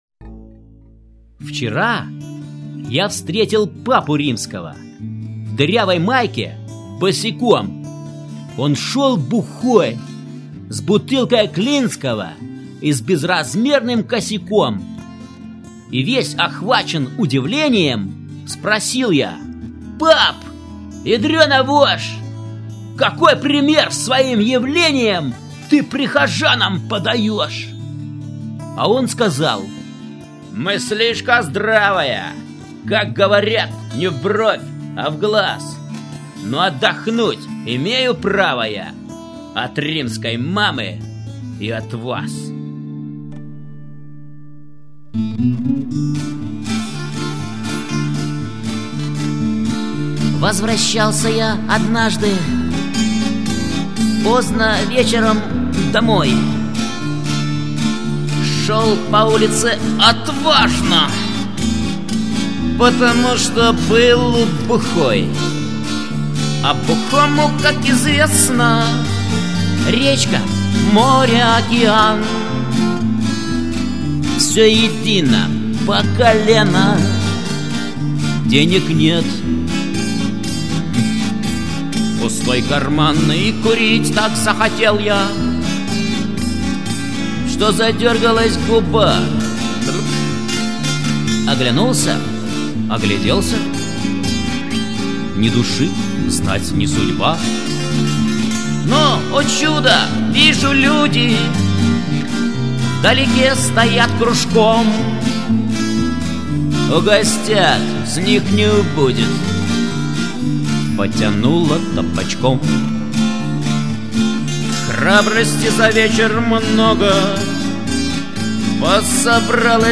~ Песни под гитару ~